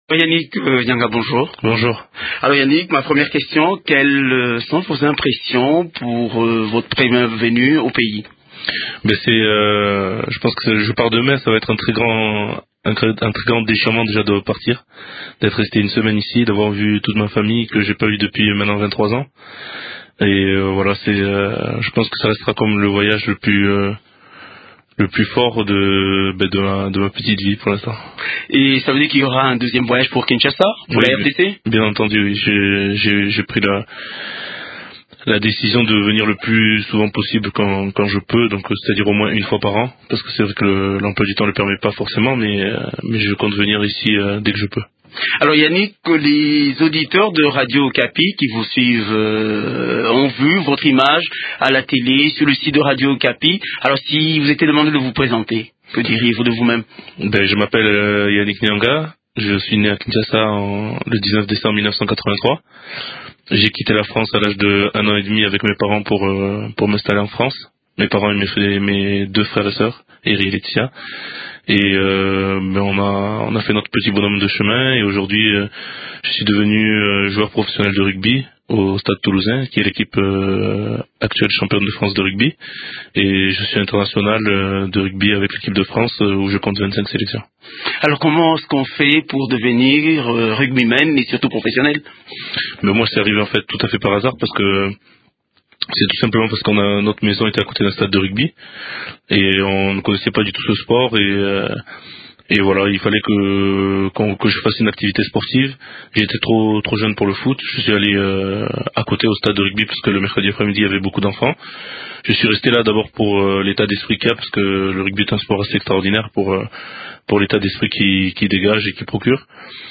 Pendant un séjourné d’une semaine, Yannick a foulé la terre de ses ancêtres, 24 ans après l’avoir quittée. Il parle de ses émotions dans un entretient